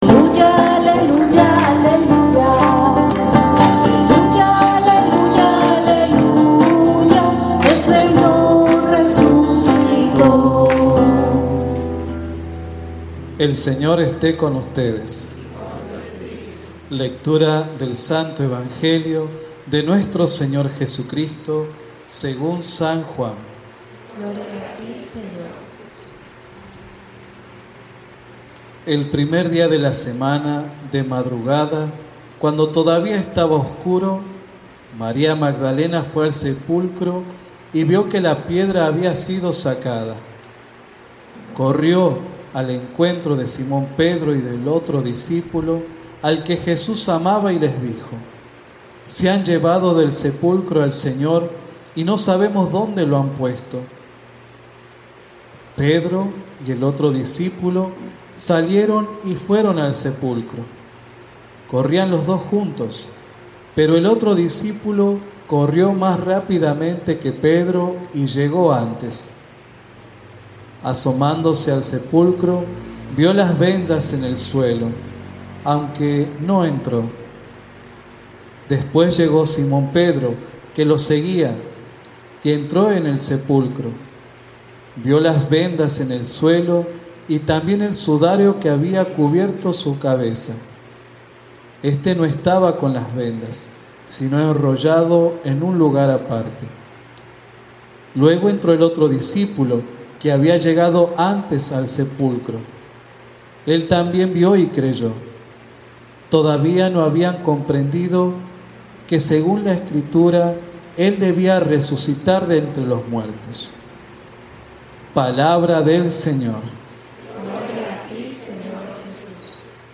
Homilia Misa Domingo Santo